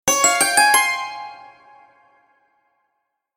دانلود آهنگ بیابان 6 از افکت صوتی طبیعت و محیط
دانلود صدای بیابان 6 از ساعد نیوز با لینک مستقیم و کیفیت بالا
جلوه های صوتی